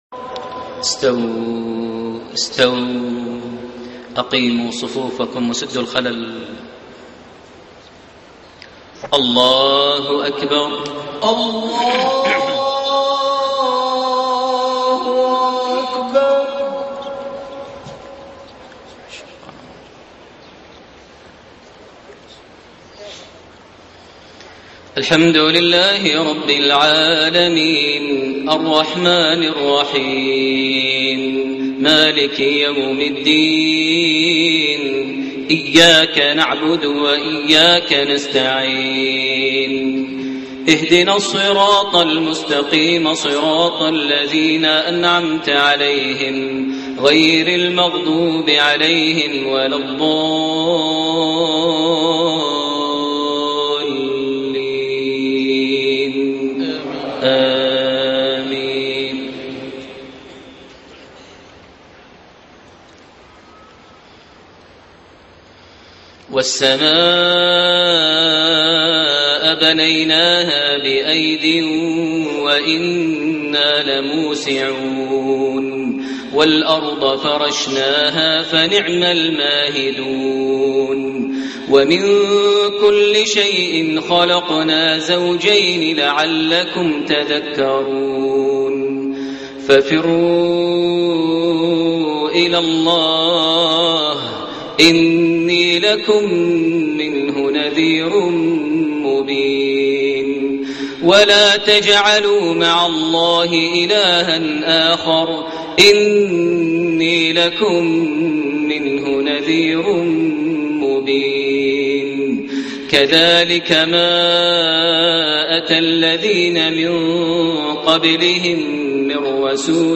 صلاة المغرب 1 رجب 1433هـ خواتيم سورة الذاريات 47-60 > 1433 هـ > الفروض - تلاوات ماهر المعيقلي